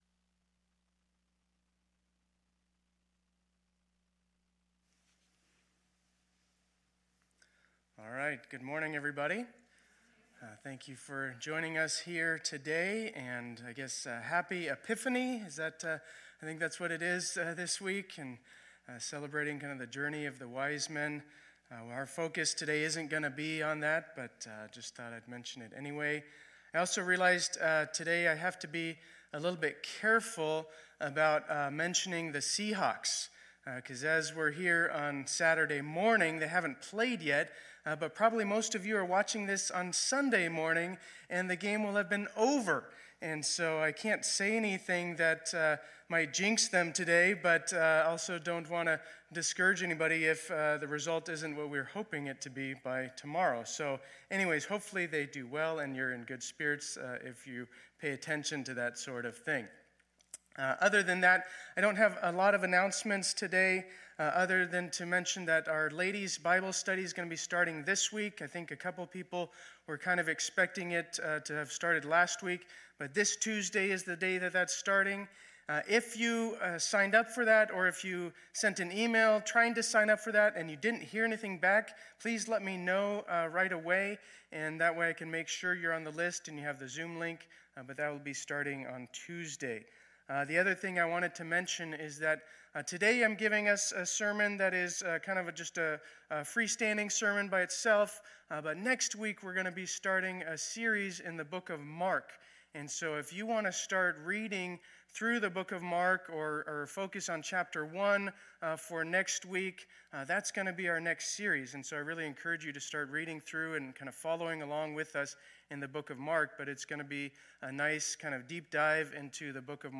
2021-01-10 Sunday Service
Intro, Teaching, and Lord’s Prayer